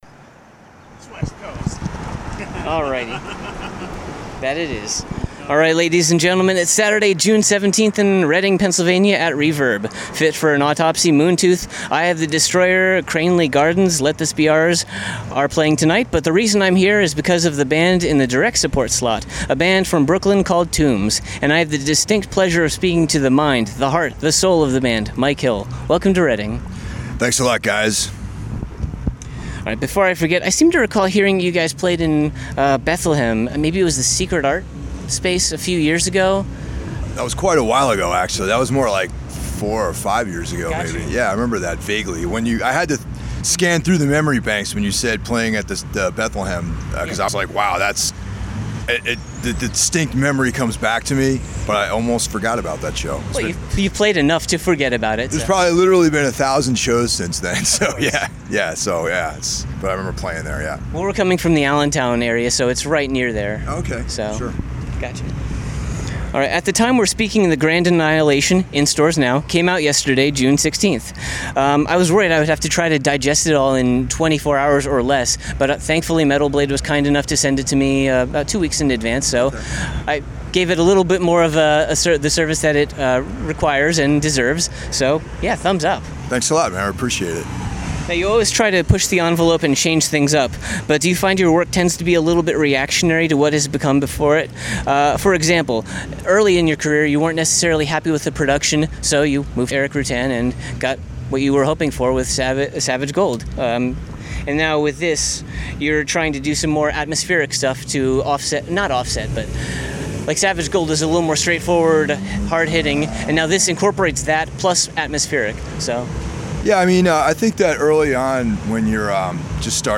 Exclusive Interview: ‘Tombs’ at Reverb (Ep 79)
79-interview-tombs.mp3